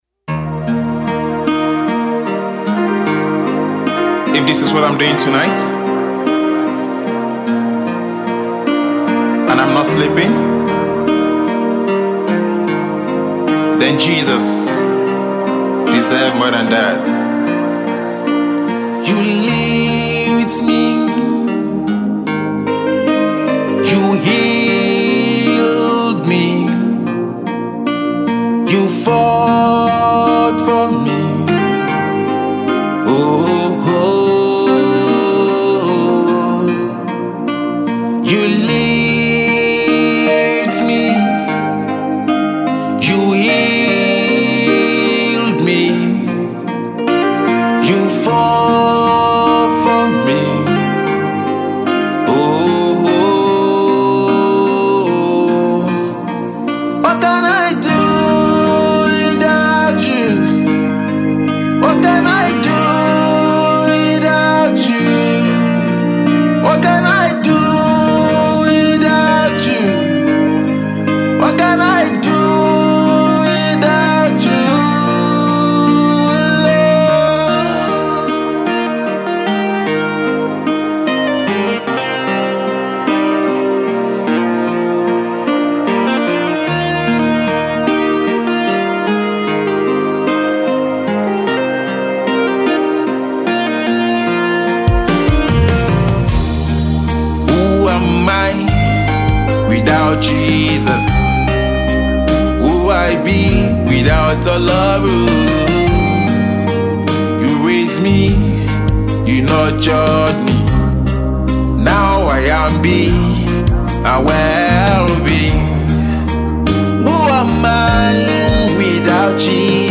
a powerful sounds of worship
female vocal power